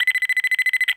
10SecondCountdown.wav